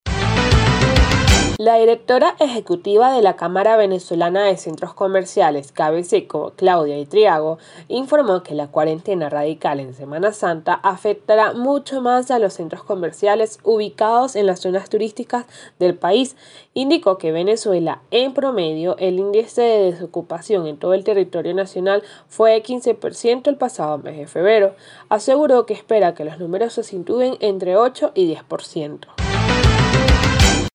Centros Comerciales en zonas turísticas son los mas afectados por cuarentena (Audio Noticia)